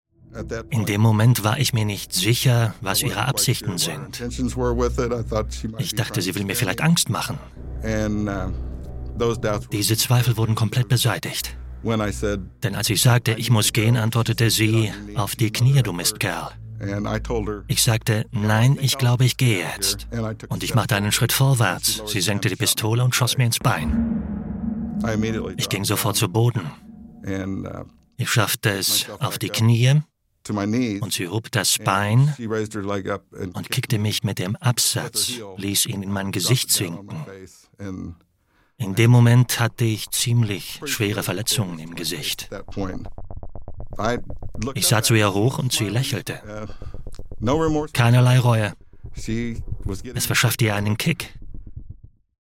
Male
Assured, Character, Cheeky, Confident, Cool, Engaging, Friendly, Natural, Smooth, Witty, Versatile, Authoritative, Corporate, Warm
DRY_STUDIO_Sample.mp3
Microphone: Neumann U87